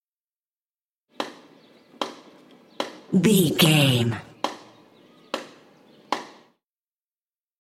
Construction ambience hit wood single
Sound Effects
ambience